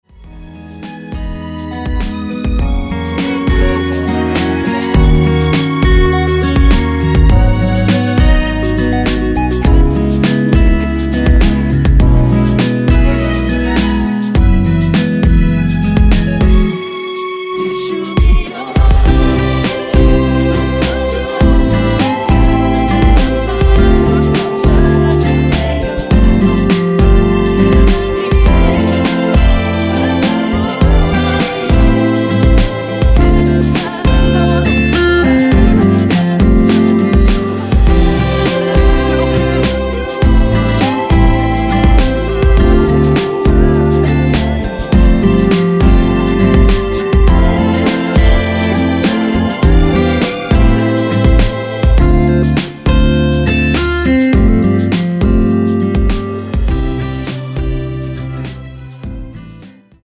원키 뒷부분 코러스 추가된 MR입니다.(미리듣기 참조)
앞부분30초, 뒷부분30초씩 편집해서 올려 드리고 있습니다.
중간에 음이 끈어지고 다시 나오는 이유는